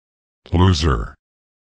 Звуки лузеров
На этой странице собраны забавные звуки лузеров – от провальных фраз до эпичных неудач.